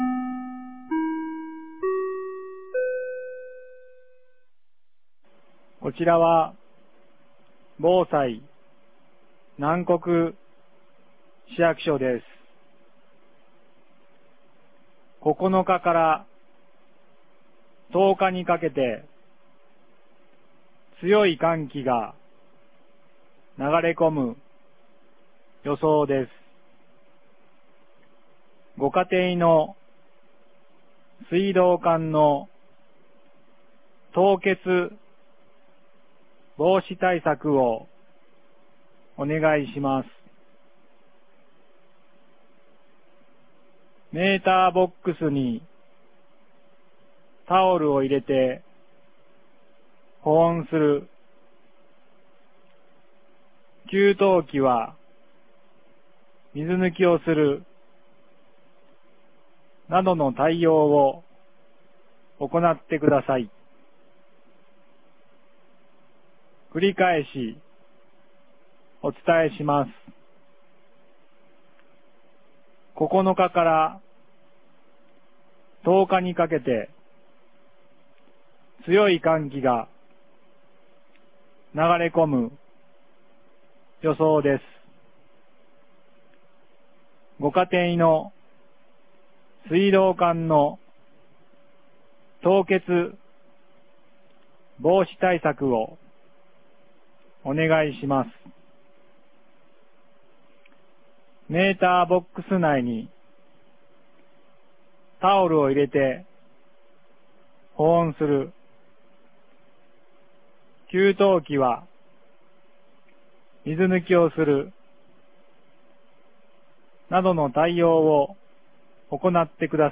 南国市放送内容
2025年01月09日 16時52分に、南国市より放送がありました。